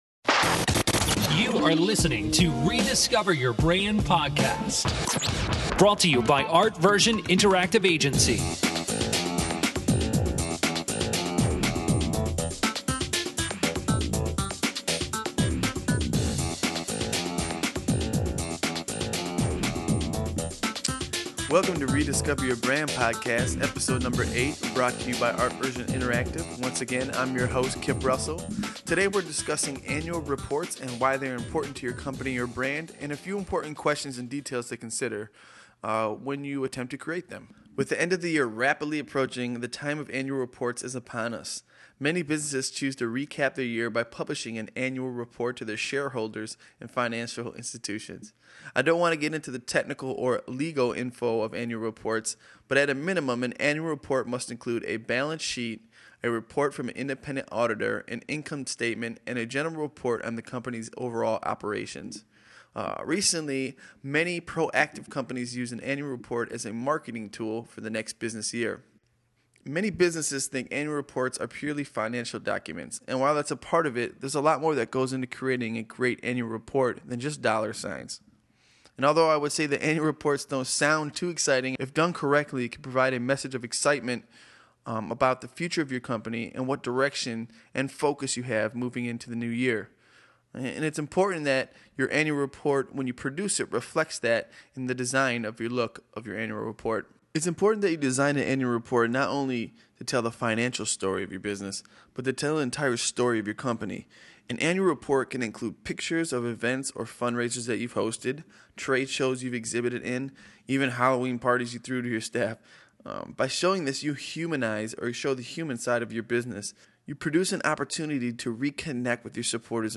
Episode 8 with some extra ad-adlibbing